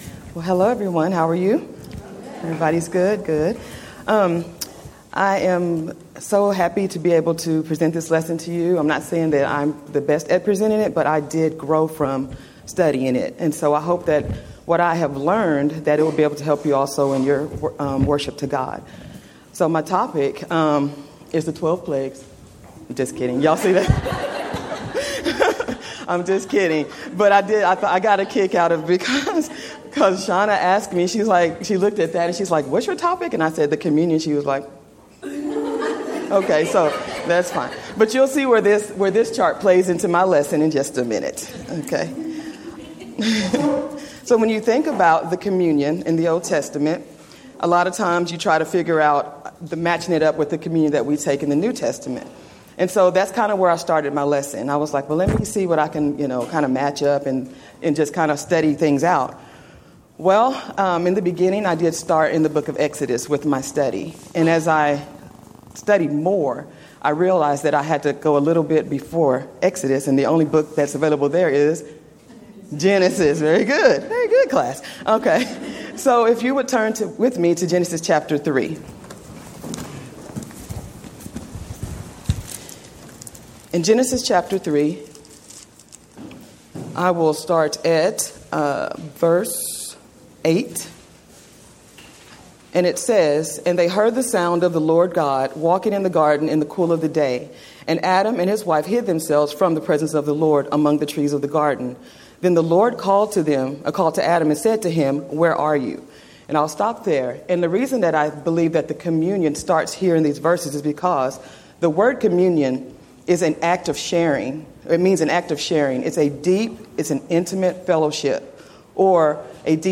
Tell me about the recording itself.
Event: 5th Annual Women of Valor Ladies Retreat